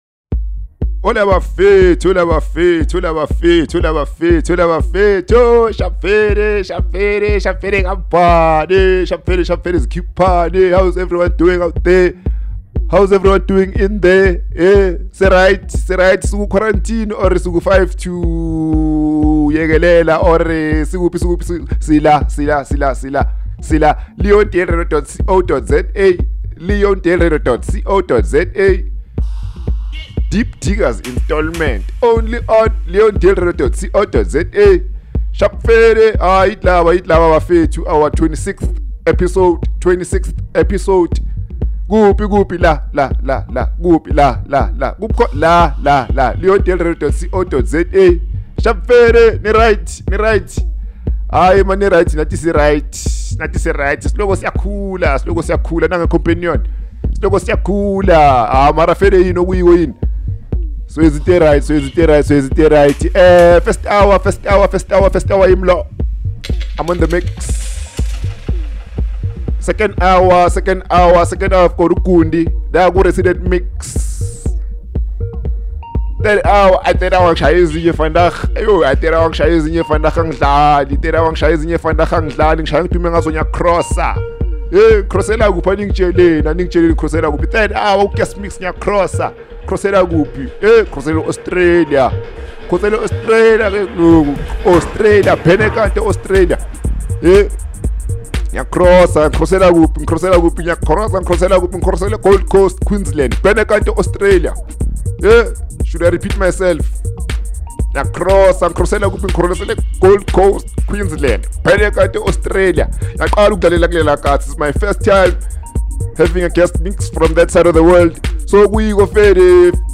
UNMIXED SELECTIONS